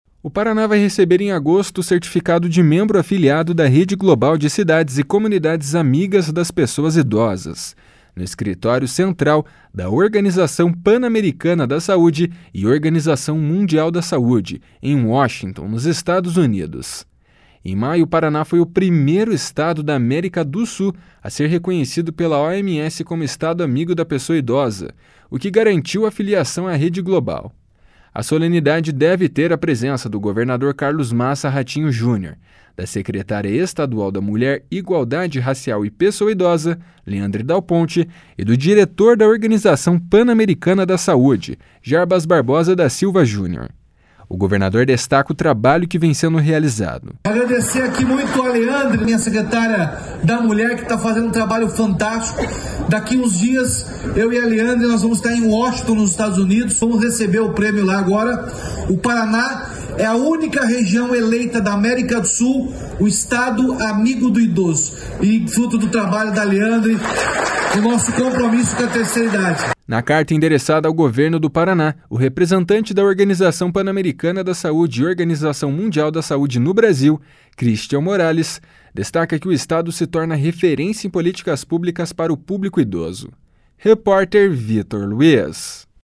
Em maio, o Paraná foi o primeiro estado da América do Sul a ser reconhecido pela OMS como Estado Amigo da Pessoa Idosa, o que garantiu a filiação à rede global. A solenidade deve ter a presença do governador Carlos Massa Ratinho Junior, da secretária estadual da Mulher, Igualdade Racial e Pessoa Idosa, Leandre Dal Ponte, e do diretor da Organização Pan-Americana da Saúde, Jarbas Barbosa da Silva Jr. O governador destaca o trabalho que vem sendo realizado.